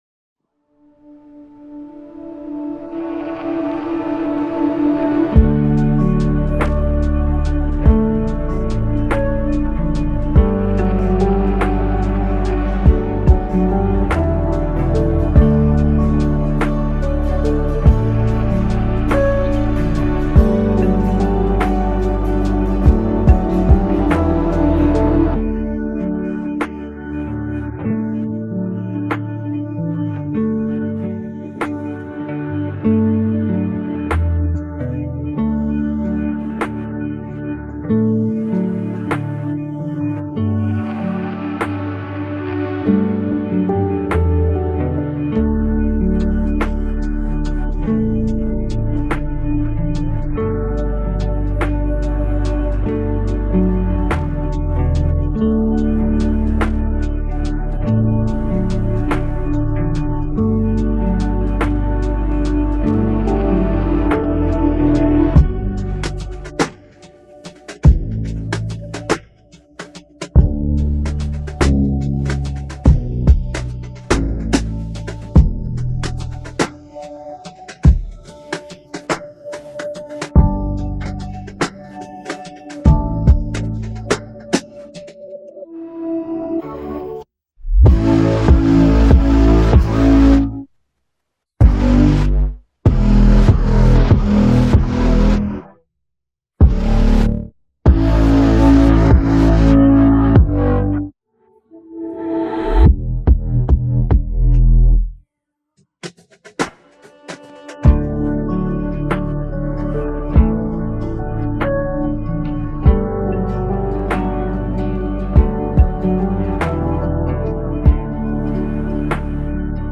90% Clean Instrumental